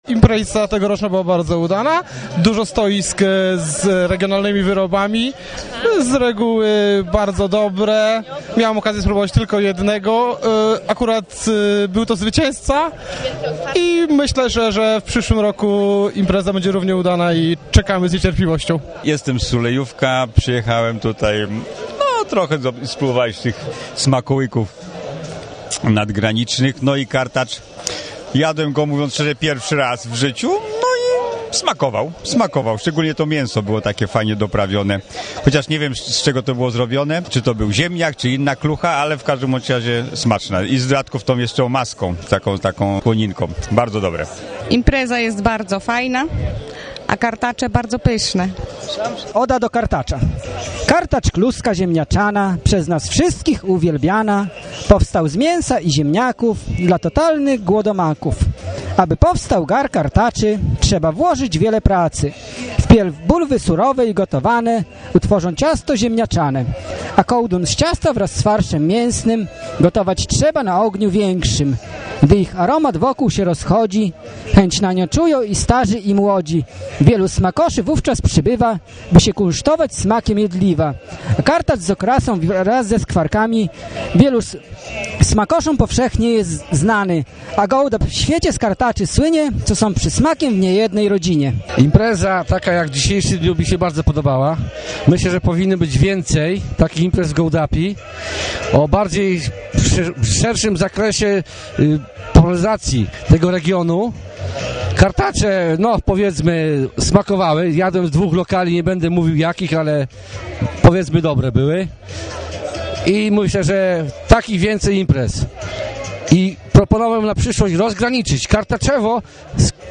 Posłuchaj, jak publiczność ocenia dzisiejszą imprezę